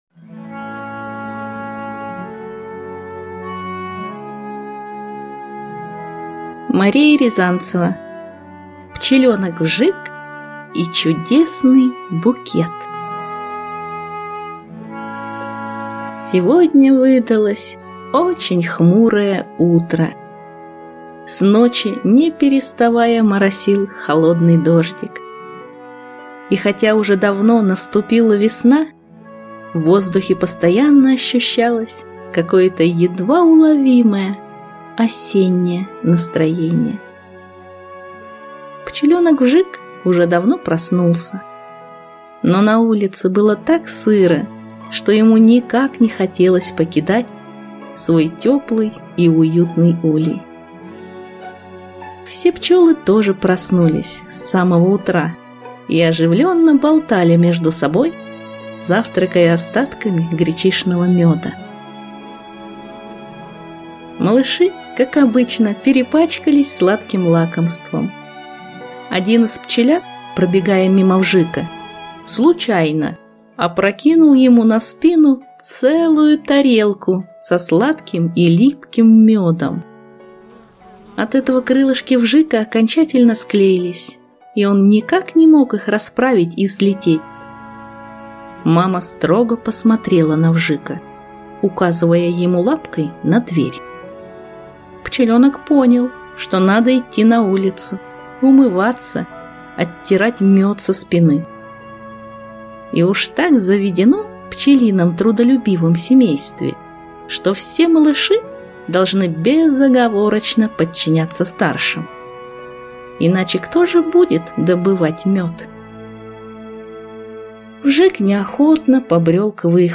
Аудиокнига Пчеленок Вжик и чудесный букет | Библиотека аудиокниг